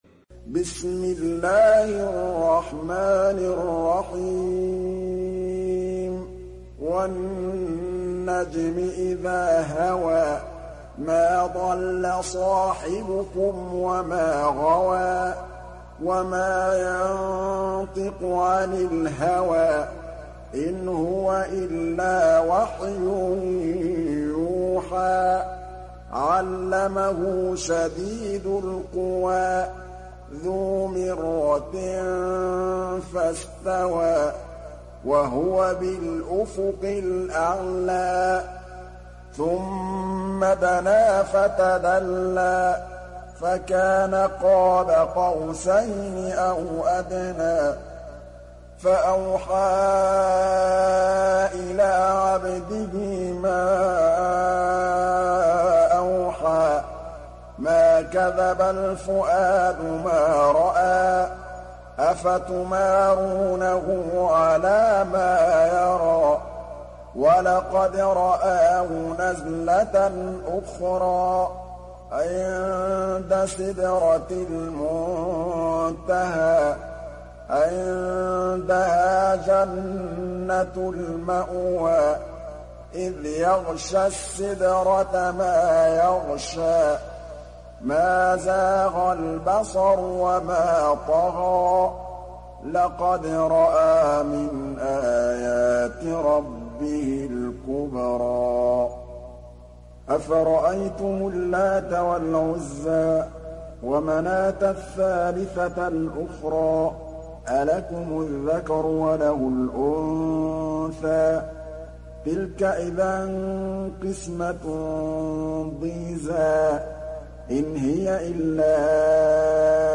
Necm Suresi mp3 İndir Muhammad Mahmood Al Tablawi (Riwayat Hafs)